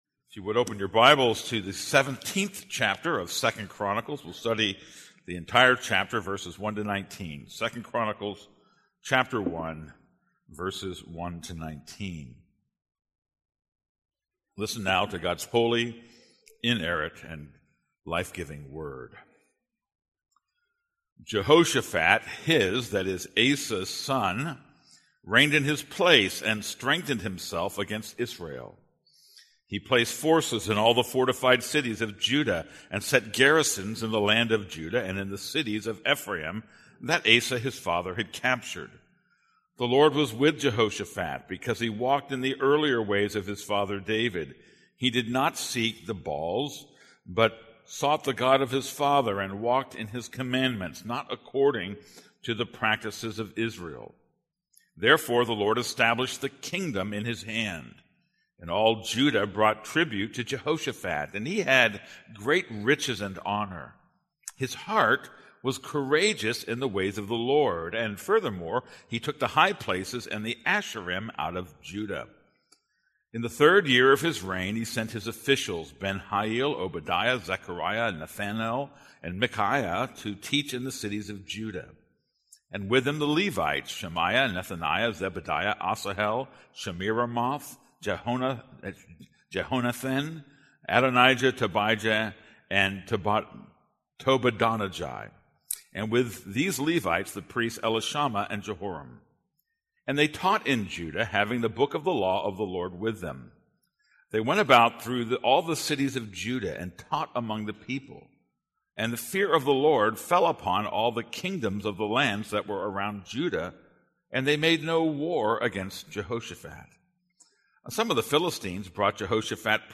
This is a sermon on 2 Chronicles 17:1-19.